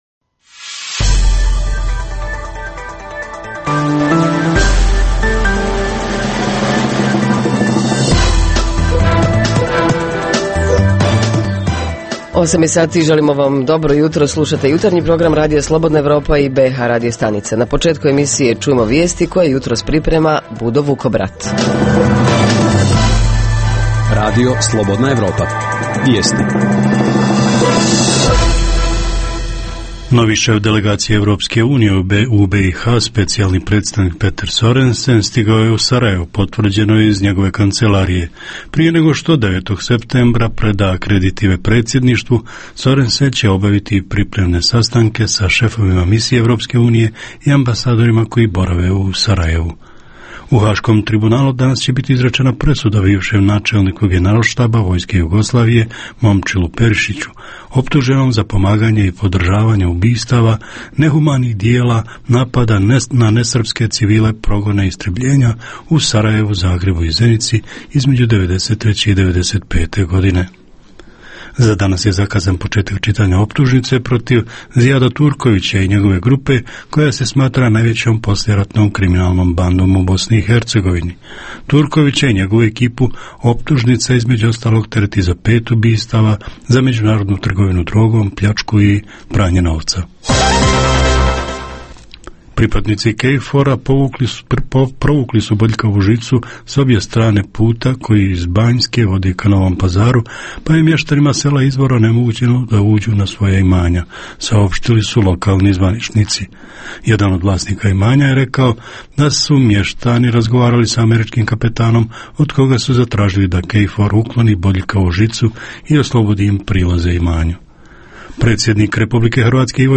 Tema jutra: da li broj predškolskih ustanova zadovoljava broj prijavljene djece, koliko košta vrtić mjesečno, kakav je smještaj i ishrana, da li su roditelji zadovoljni? Reporteri iz cijele BiH javljaju o najaktuelnijim događajima u njihovim sredinama.
Redovni sadržaji jutarnjeg programa za BiH su i vijesti i muzika.